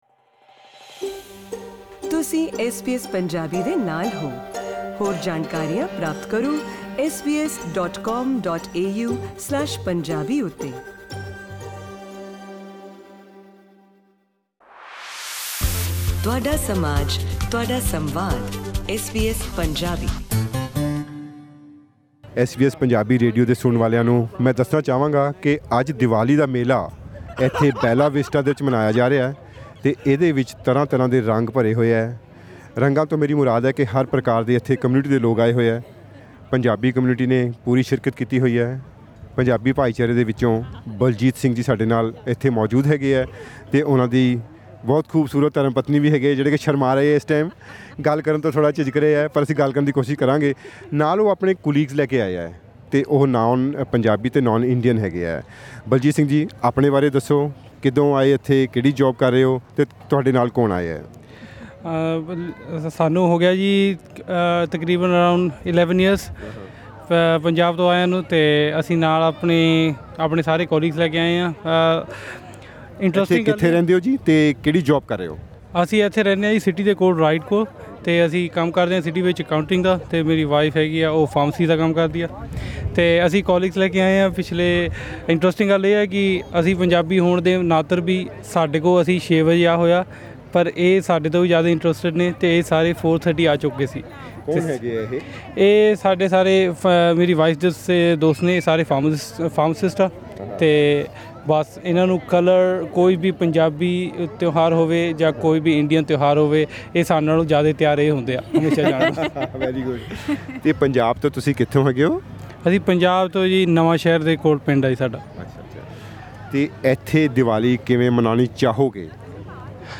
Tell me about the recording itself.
SBS Punjabi spoke to many visitors at Bella Vista Diwali festival who shared their thoughts on how they would like to celebrate Diwali in Australia. Many had invited their friends with non-Indian heritage, and they celebrated the day with as much fervour too.